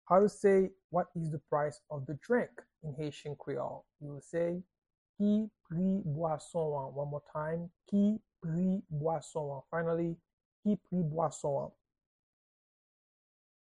Pronunciation and Transcript:
How-to-say-Whats-the-price-of-the-drink-in-Haitian-Creole-–-Ki-pri-bwason-an-pronunciation.mp3